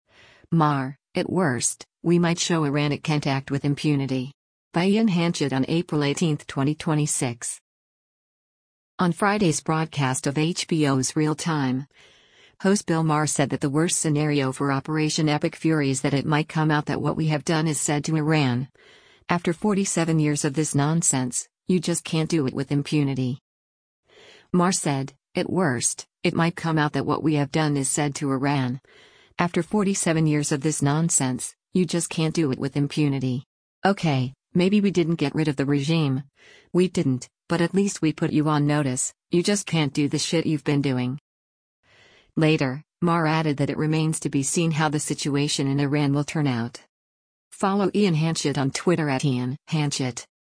On Friday’s broadcast of HBO’s “Real Time,” host Bill Maher said that the worst scenario for Operation Epic Fury is that it “might come out that what we have done is said to Iran, after forty-seven years of this nonsense, you just can’t do it with impunity.”